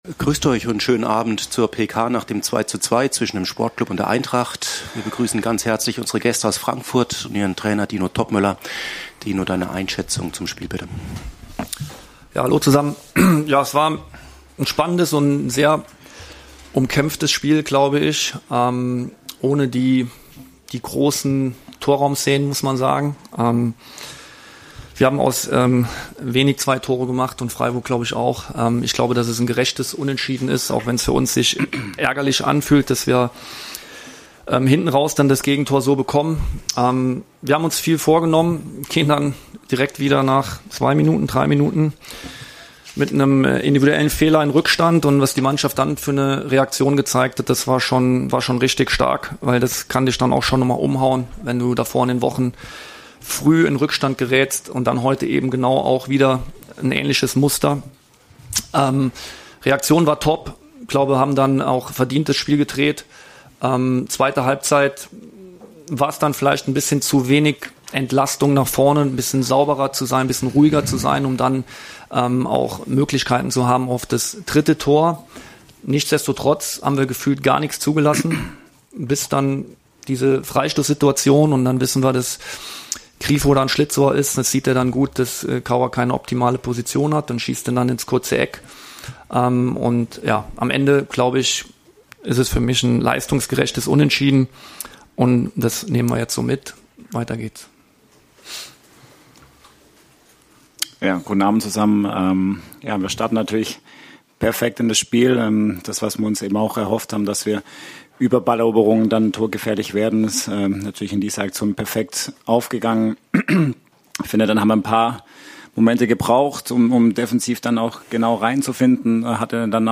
Die Pressekonferenz nach den 90 Minuten im Breisgau mit den beiden Trainern Dino Toppmöller und Julian Schuster.